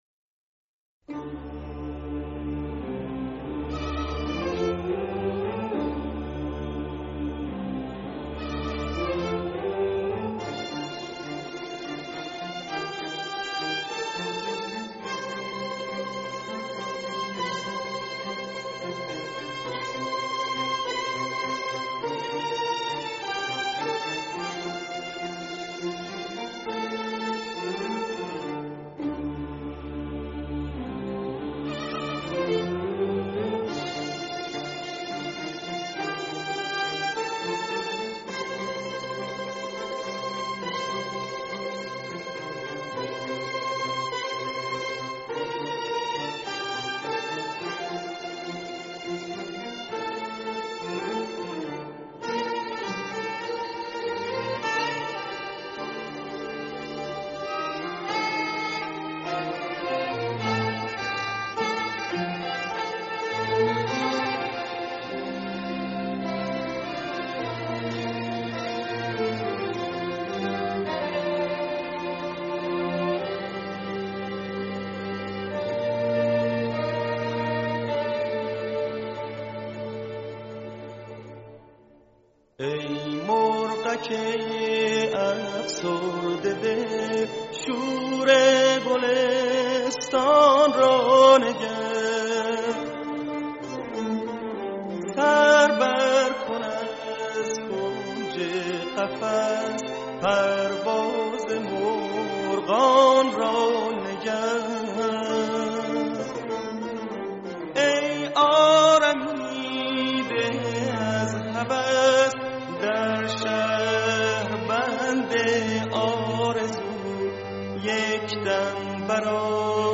تصنیف ارکسترال دشتی